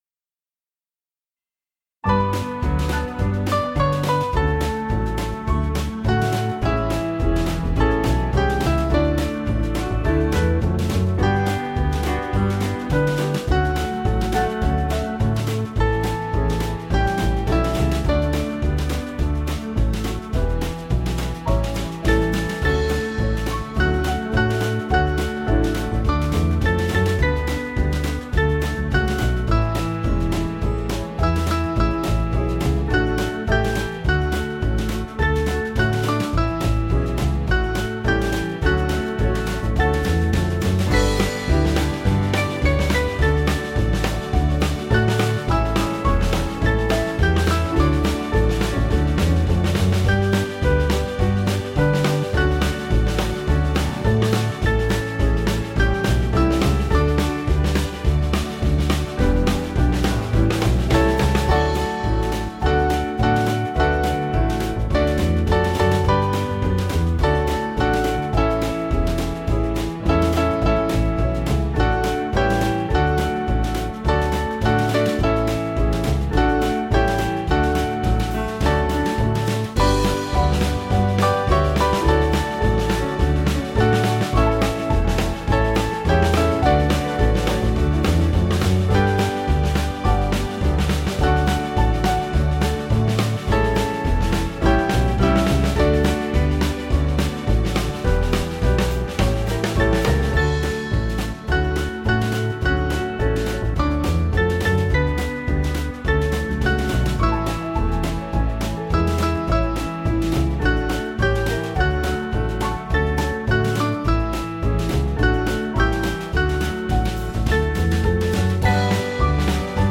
Small Band
(CM)   4/D-Eb